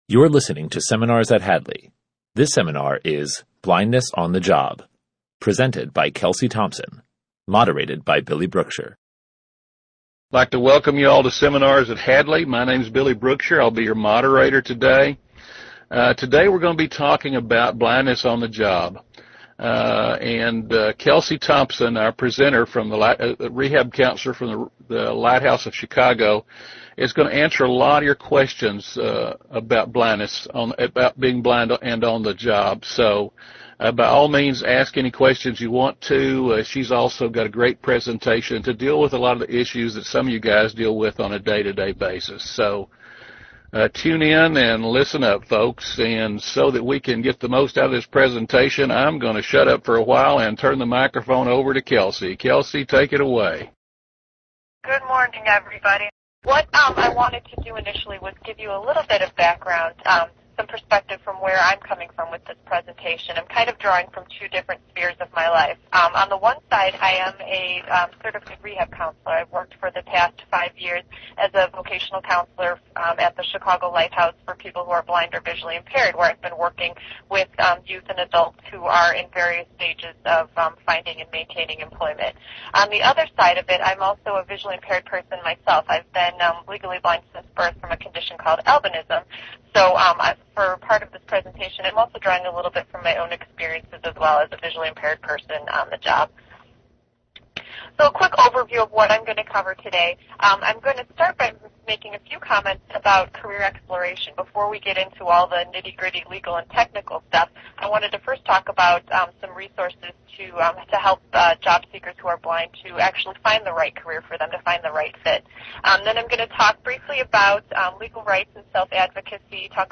In this seminar provided by the Hadley School for the Blind the following topics will be discussed:
BLINDNESS_ON_THE_JOB_seminar.mp3